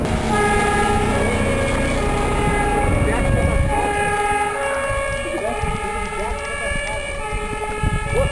Ein Feuerwehrfahrzeug entfernt sich zunächst.
Abb. 03: Frequenzverschiebung durch bewegten Sender.  Ein Feuerwehrfahrzeug fährt weg, es entfernt sich zunächst mit konstanter Geschwindigkeit.